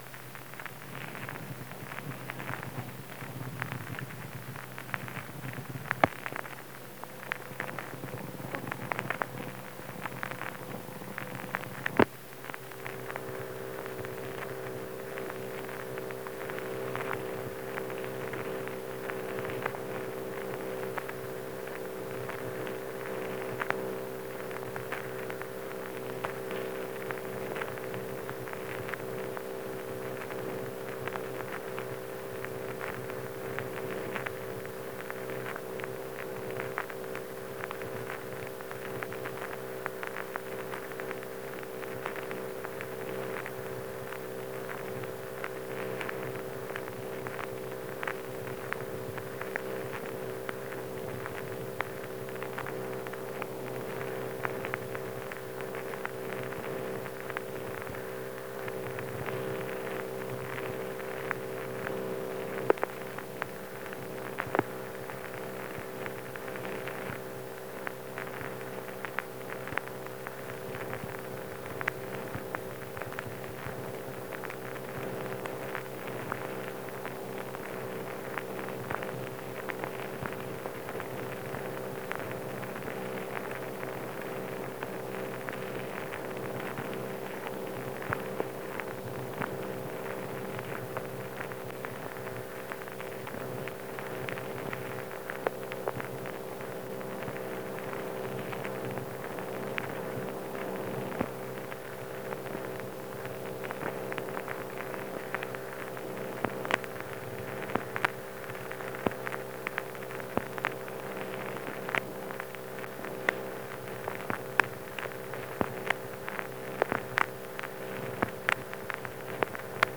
Unidentified conversation
Secret White House Tapes | John F. Kennedy Presidency Unidentified conversation Rewind 10 seconds Play/Pause Fast-forward 10 seconds 0:00 Download audio Previous Meetings: Tape 121/A57.